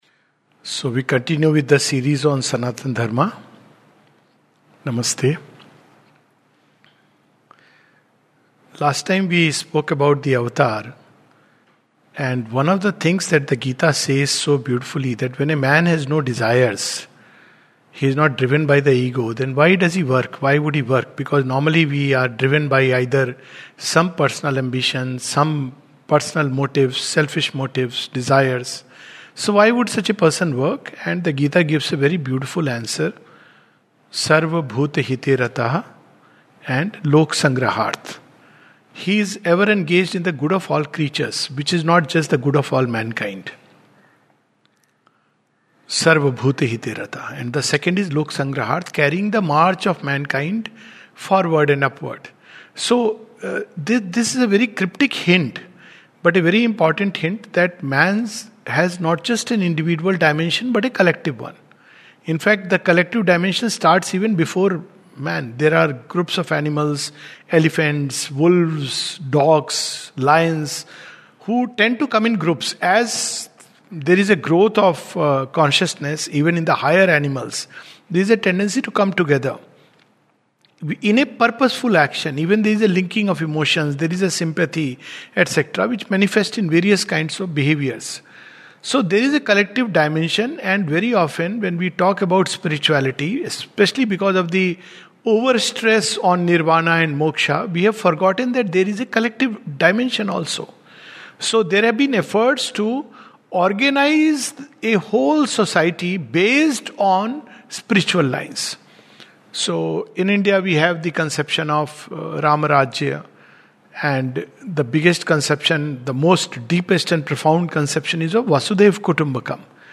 This talk